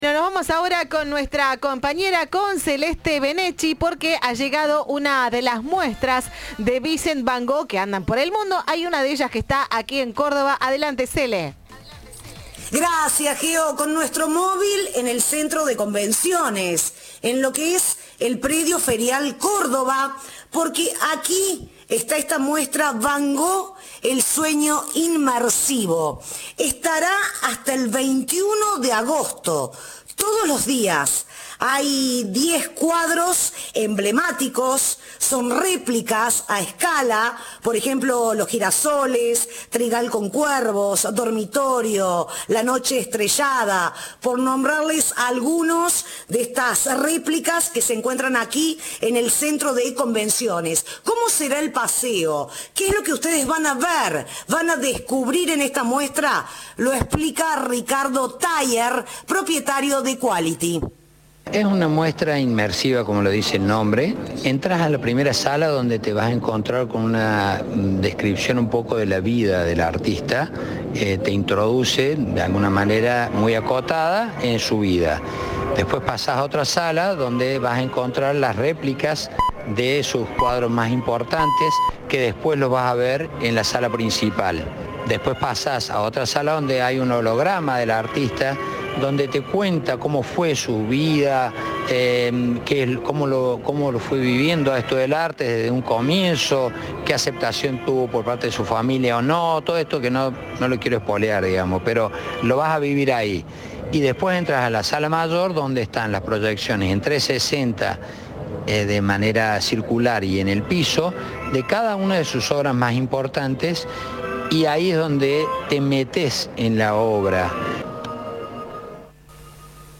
En diálogo con Cadena 3